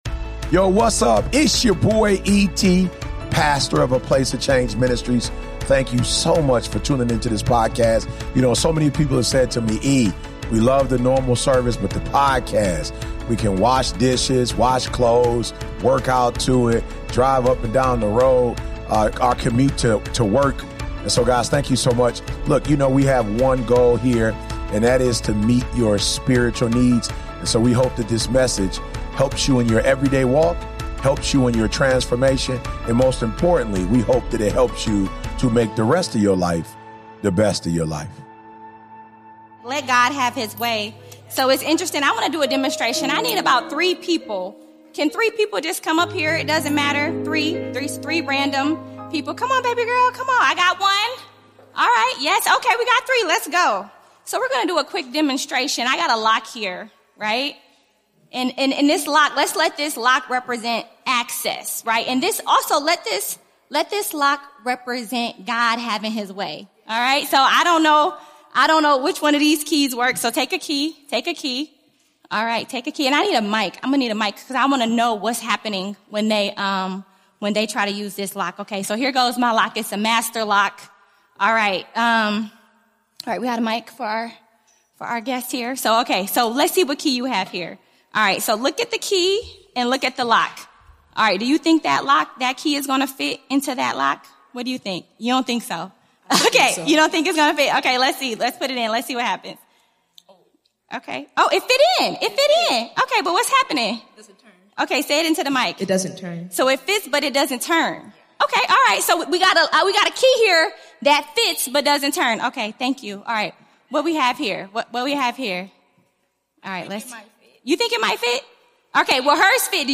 You'll laugh, reflect, and be challenged as she uses real-life stories — from a midnight run-in in Detroit to corporate burnout and spiritual resistance — to reveal how misalignment can show up even when you think you're doing the right thing.